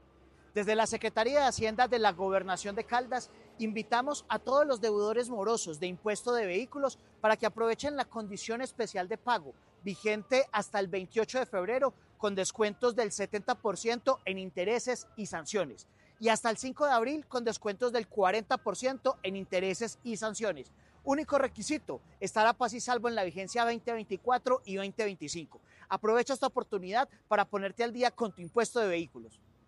Secretario de Hacienda de Caldas, John Alexander Alzate Quiceno.